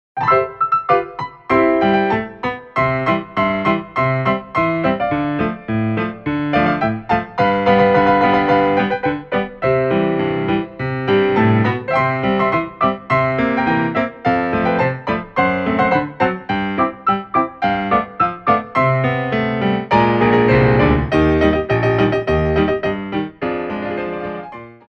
2/4 (16x8) + Stop Time